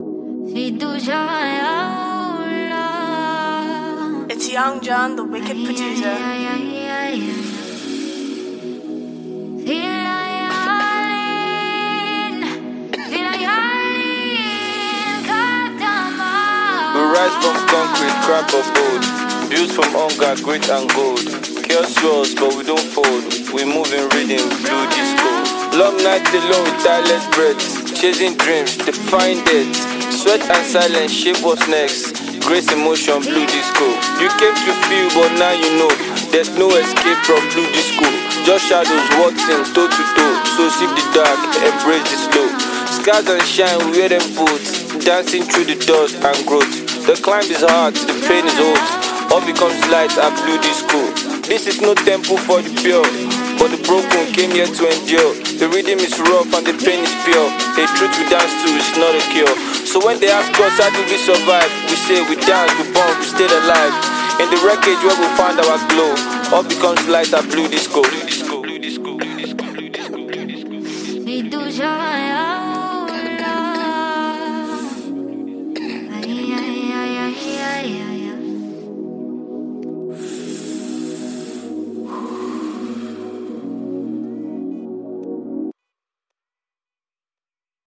smooth and reflective track
Built on soft melodies and a steady, soothing rhythm